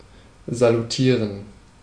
Ääntäminen
Ääntäminen US : IPA : [səˈluːt] Haettu sana löytyi näillä lähdekielillä: englanti Käännös Ääninäyte Substantiivit 1.